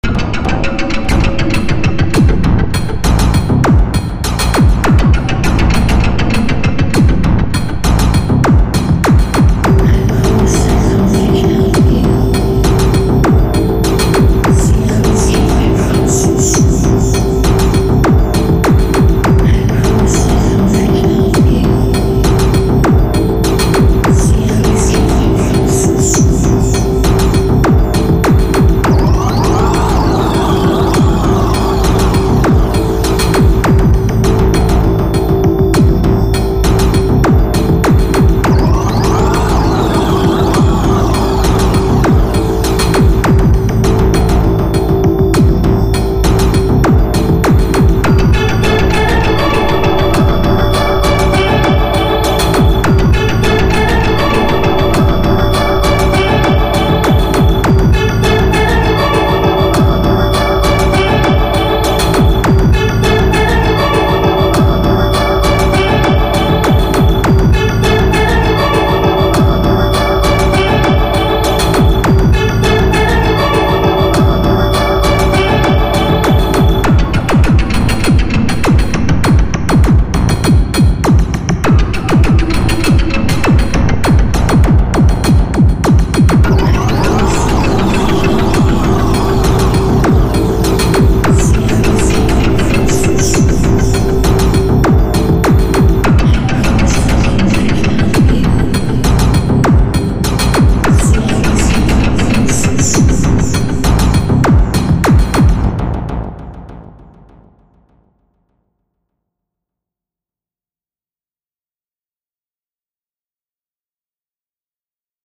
Avantgarde